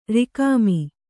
♪ rikāmi